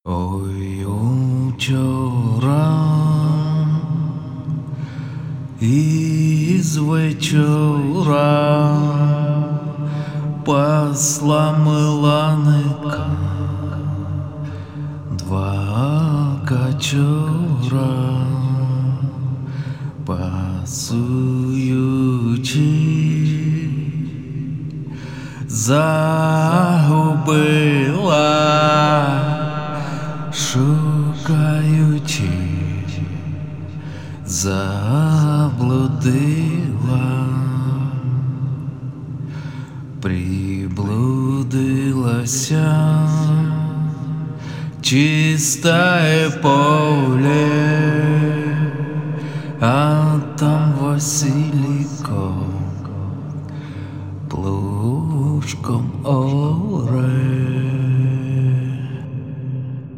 Я действую таким методом: Берешь айфон, включаешь метроном, накручиваешь эхо, что б не так ужасно все было, поешь без муз инструментов. Я выбрал украинскую песню, переделал на свой слух, записал, послушал. Услышал, что не попадаешь в ноты, всякие выделывания сюда не идут, тембр просится другой, какой пока не знаю, буду подбирать, акценты не нравятся, с закрытым ртом тоже не поют, настроение не такое и т.д. И работаешь дальше по каждому направлению, пока самому не понравится. Как образец исполнения, который мне не нравится: Вложения Маланка.mp3 Маланка.mp3 2,4 MB · Просмотры: 809